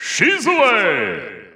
The announcer saying Isabelle's name in Japanese and Chinese releases of Super Smash Bros. Ultimate.
Isabelle_Japanese_Announcer_SSBU.wav